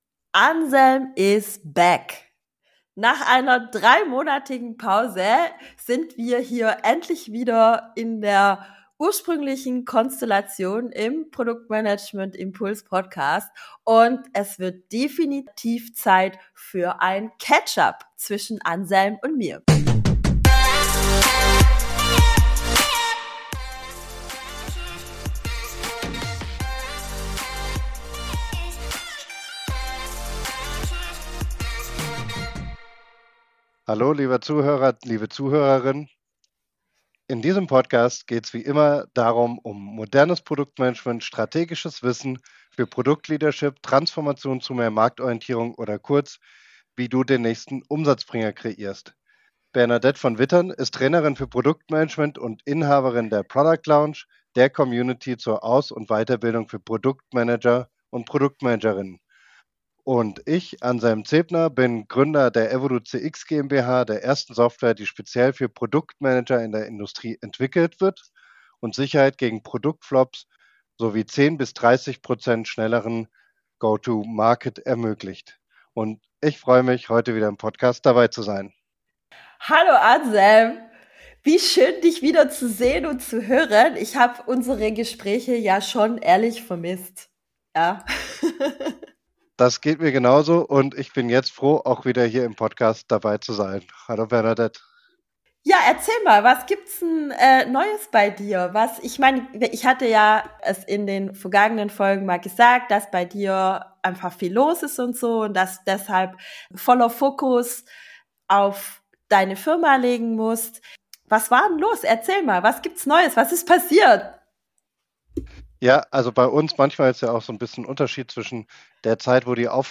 Es ist ein ehrliches, persönliches Gespräch über Wachstum, Loslassen, neue Technologien und die Leidenschaft für gutes Produktmanagement geworden.